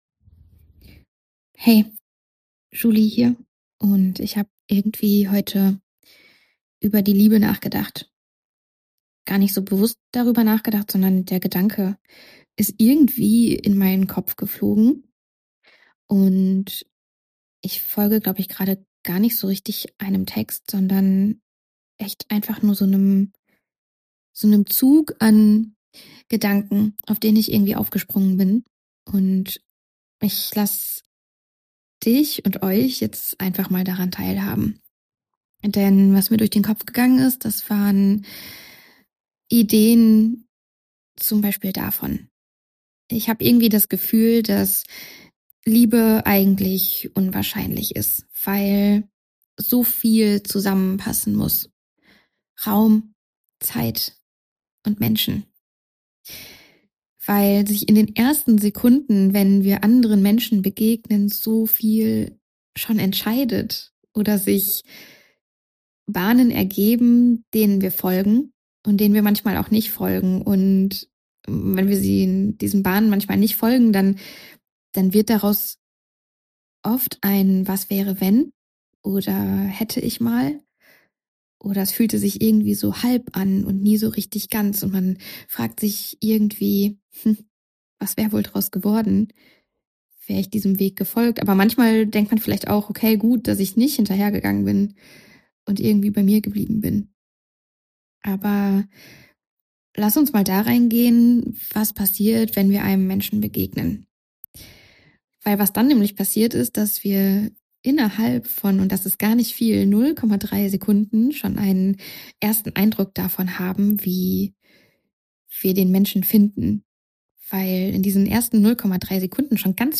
Stream of thoughts über das wohl schönste Gefühl, was es auf der Welt gibt: die Liebe. :) Ich bin einfach mal meinem Gefühl gefolgt, keinem geordneten Text.